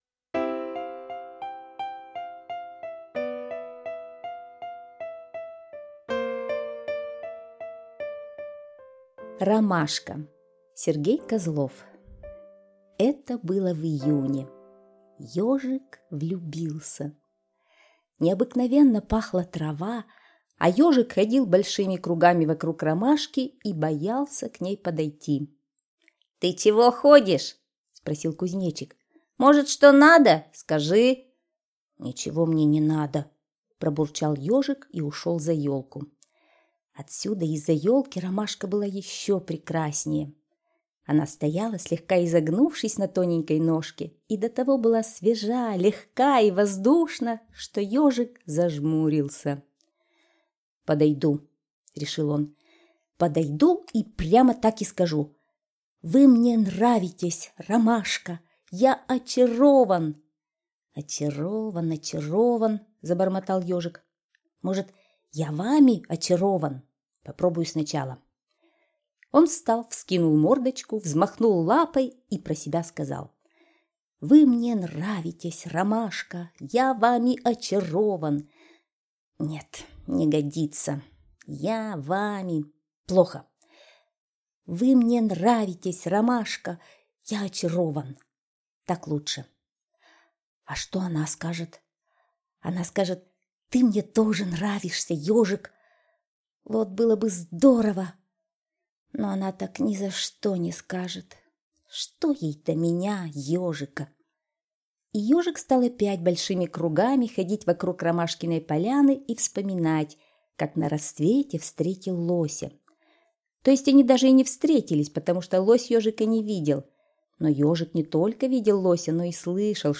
Слушайте Ромашка - аудиосказка Козлова С.Г. Сказка про то, как в лесу распустилась белоснежная ромашка и Ежик в нее влюбился.